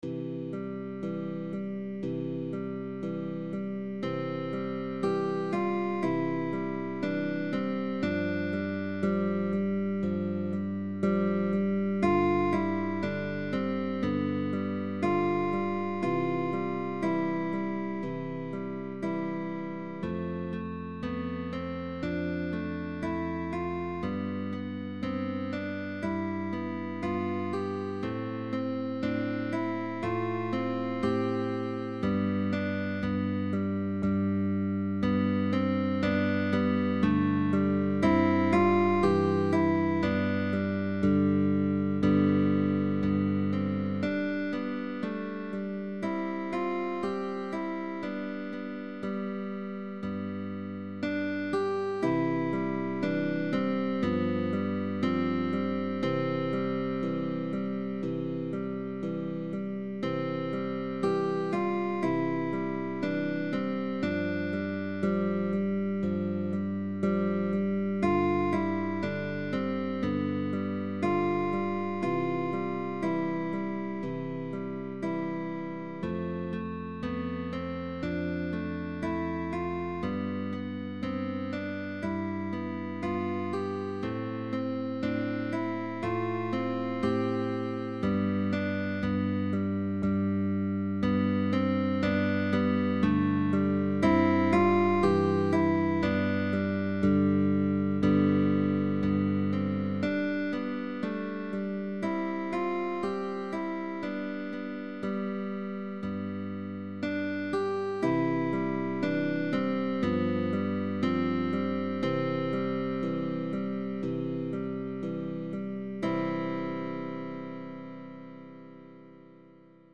Baroque